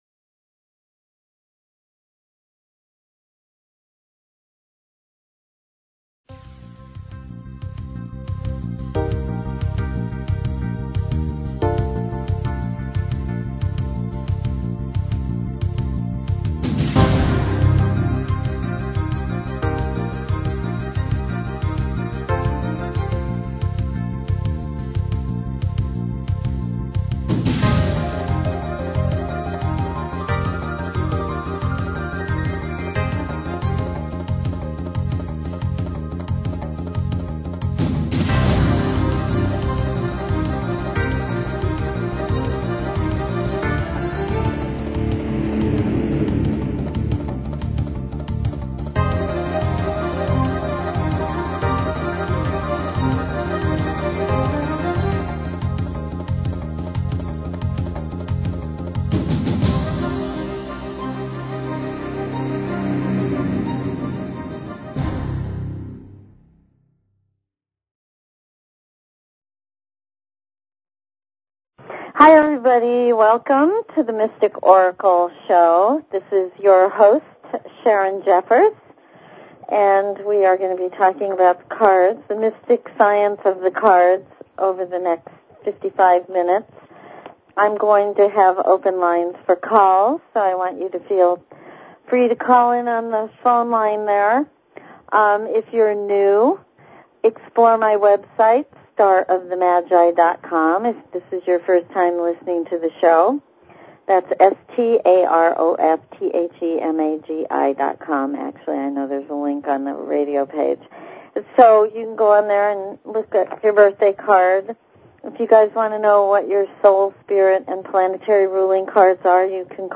Talk Show Episode, Audio Podcast, The_Mystic_Oracle and Courtesy of BBS Radio on , show guests , about , categorized as
Open lines for calls.